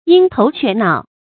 鷹頭雀腦 注音： ㄧㄥ ㄊㄡˊ ㄑㄩㄝˋ ㄣㄠˇ 讀音讀法： 意思解釋： 形容相貌丑陋而神情狡猾。